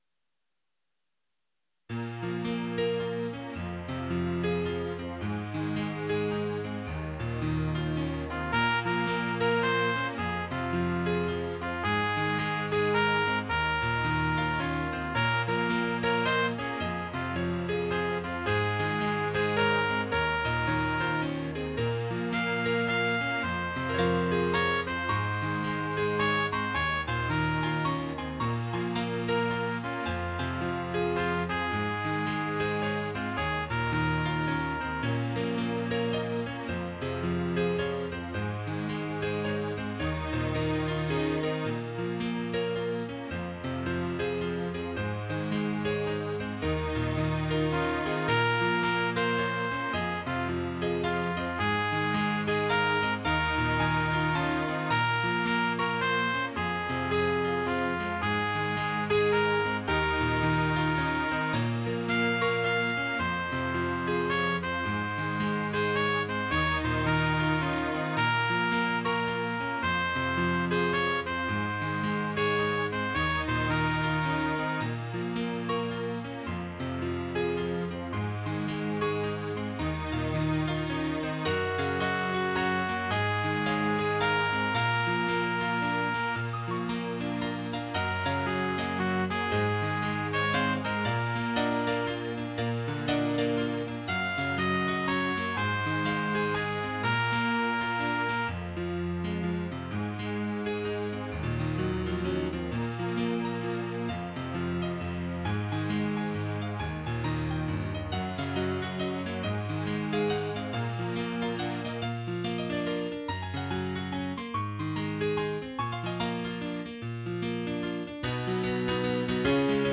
Tags: Duet, Piano, Clarinet, Brass
282 Reflection - trumpet.wav